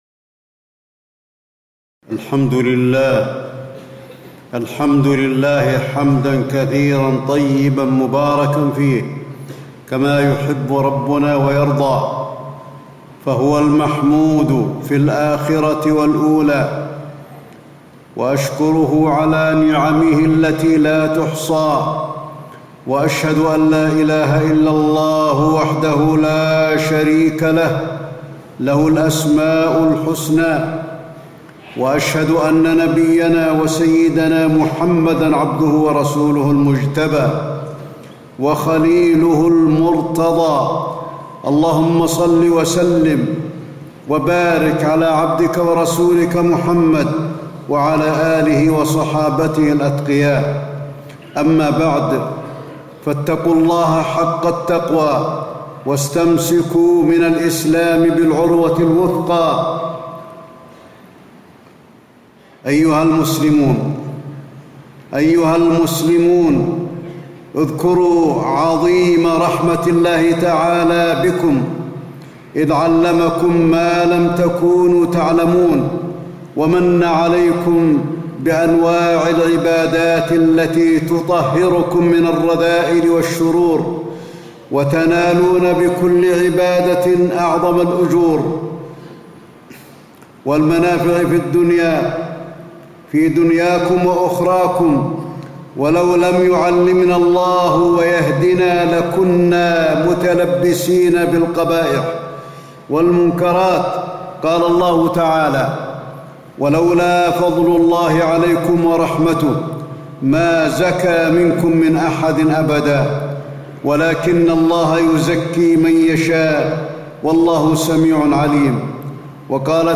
تاريخ النشر ٥ ذو الحجة ١٤٣٦ هـ المكان: المسجد النبوي الشيخ: فضيلة الشيخ د. علي بن عبدالرحمن الحذيفي فضيلة الشيخ د. علي بن عبدالرحمن الحذيفي آداب الحج